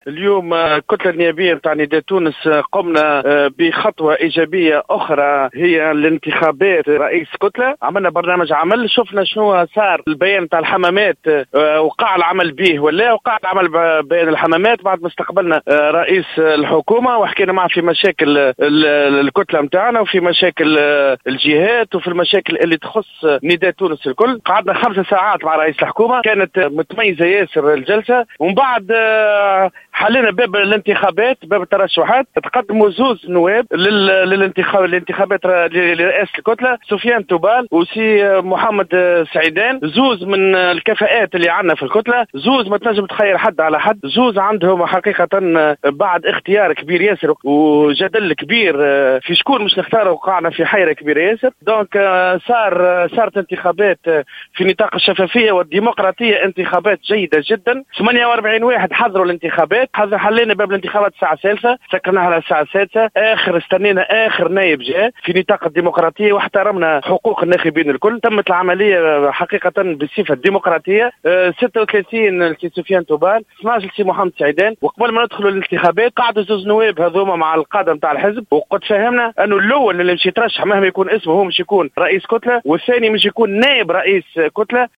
Dans une déclaration accordée à Jawhara FM, le député de Nidaa Tounes, Fayçel Khelifa, a indiqué que Sofien Toubel a été élu aujourd'hui 7 mai 2016 président du groupe parlementaire de Nidaa Tounes en remplacement du démissionnaire Mohamed Fadhel Omrane.